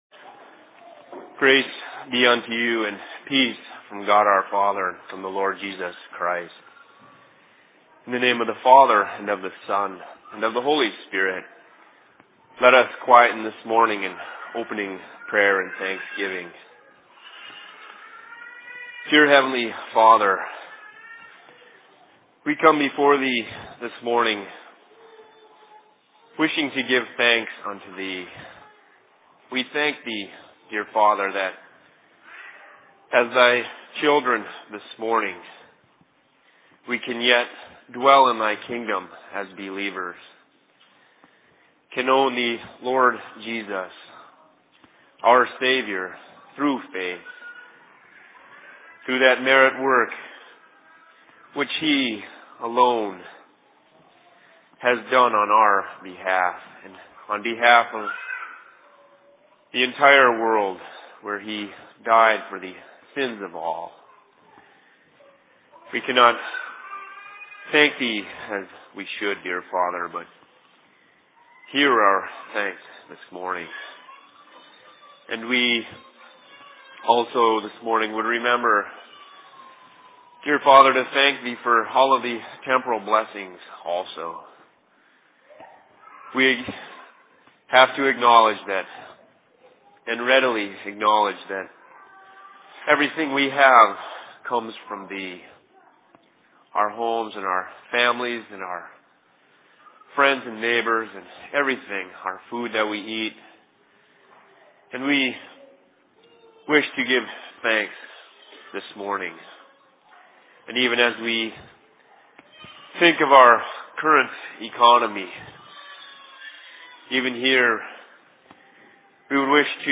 Sermon in Seattle 19.10.2008